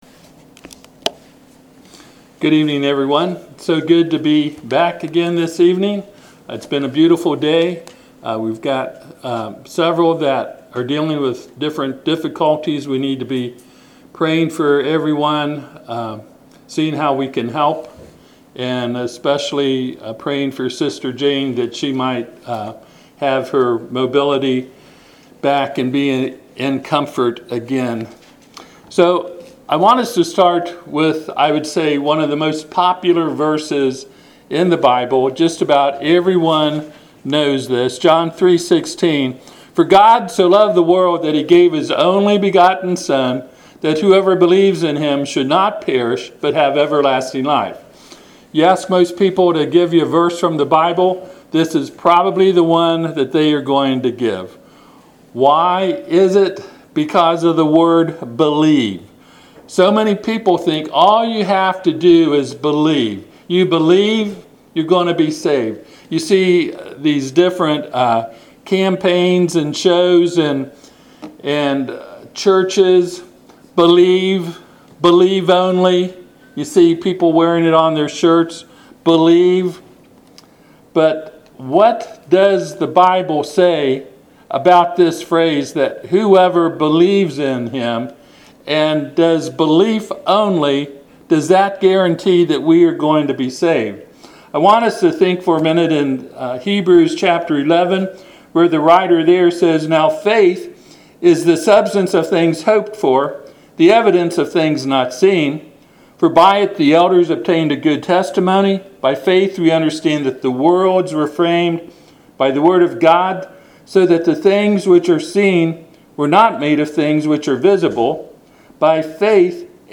Passage: John 3:16 Service Type: Sunday PM